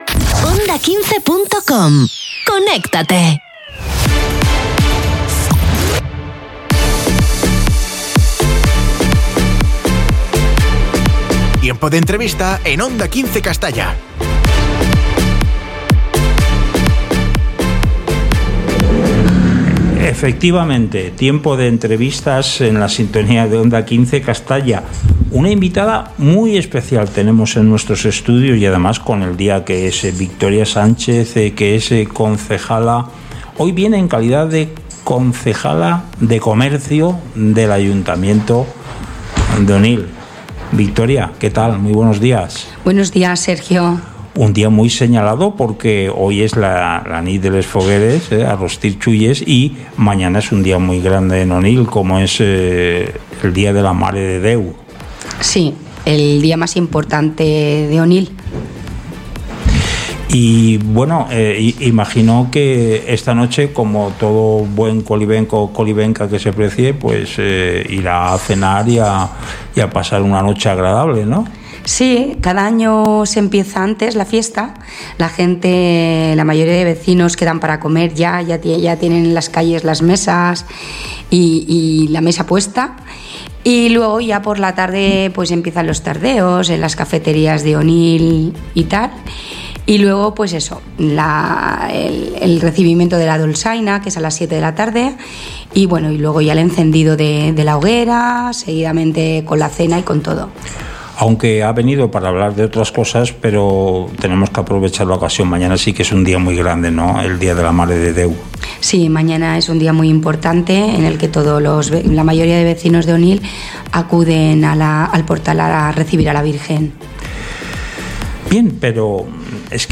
Hoy en nuestro Informativo hemos hablado con Victoria Sánchez, concejala de Comercio del Ayuntamiento de Onil.